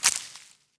harvest_2.wav